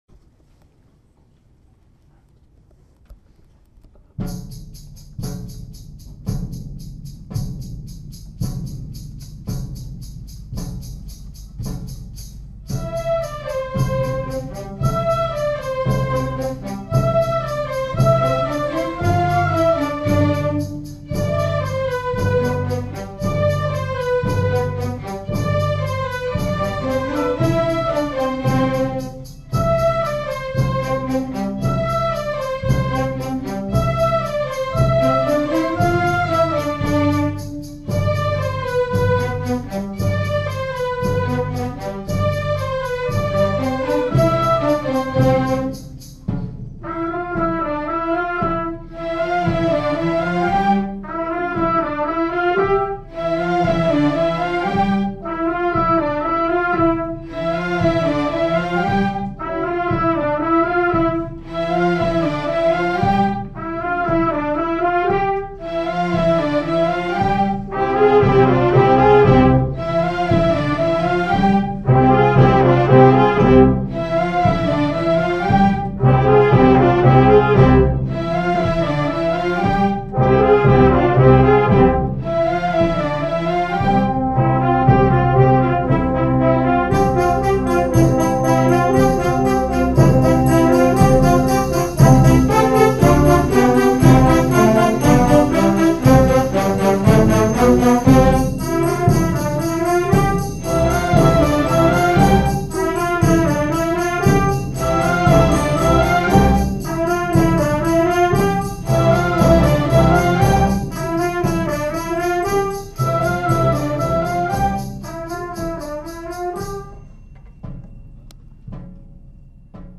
For Orchestra (Grade 3/4)